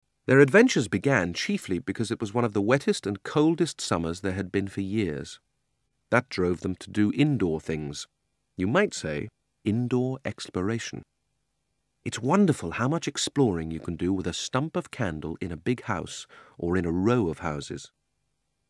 But each one is read by a different actor, and they’re all great.